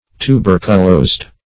Tuberculosed \Tu*ber"cu*losed`\, a.
tuberculosed.mp3